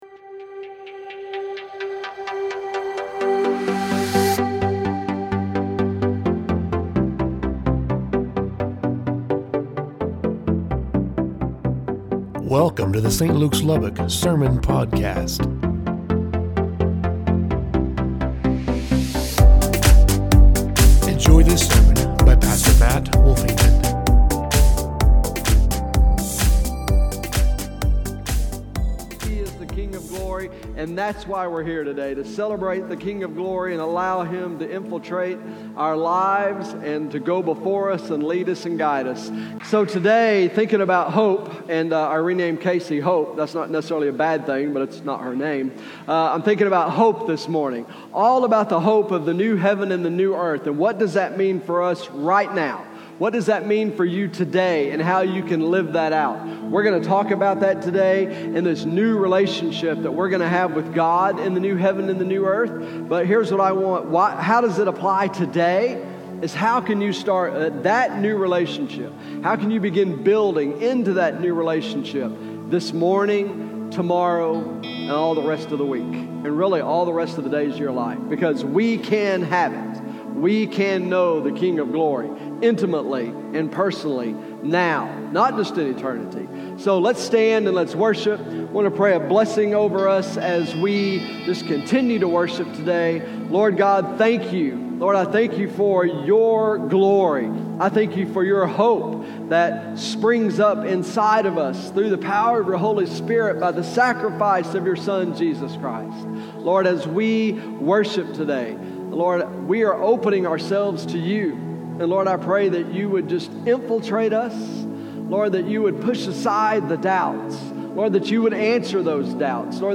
Service Type: Southwest Campus